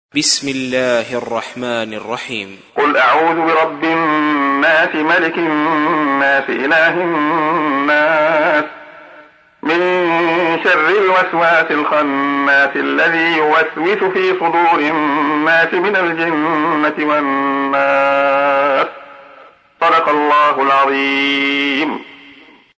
تحميل : 114. سورة الناس / القارئ عبد الله خياط / القرآن الكريم / موقع يا حسين